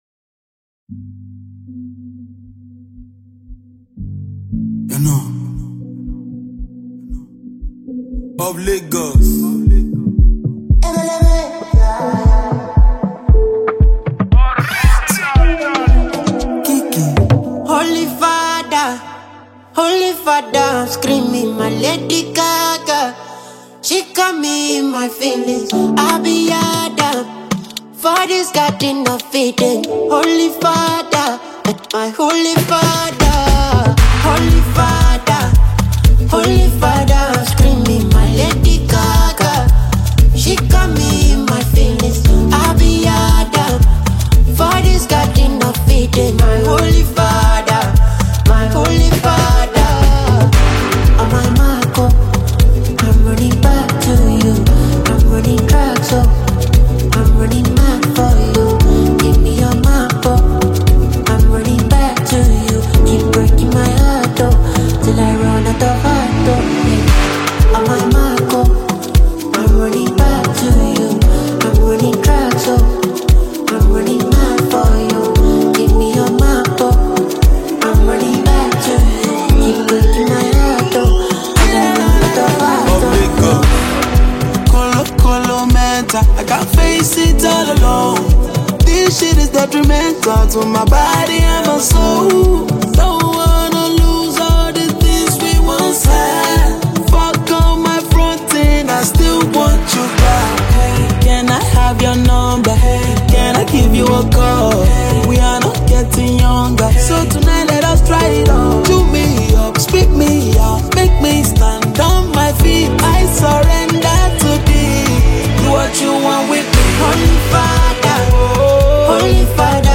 A-List Nigerian singer and songwriter